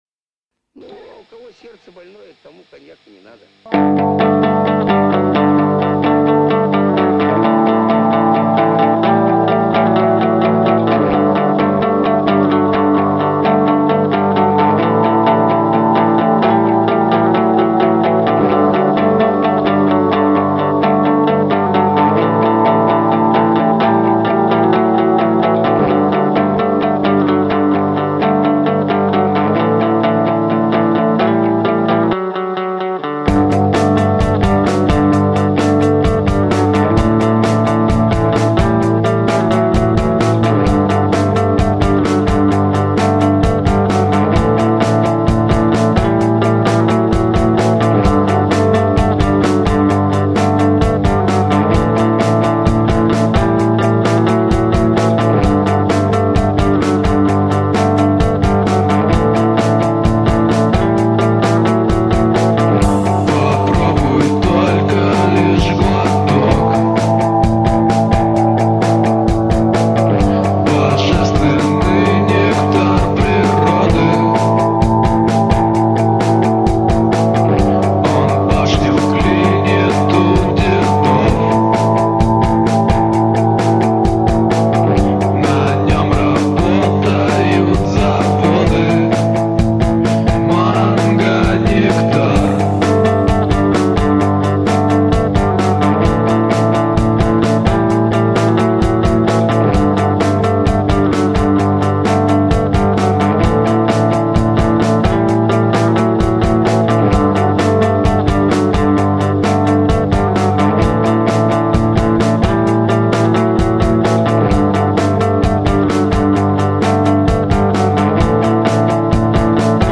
资料描述: 后朋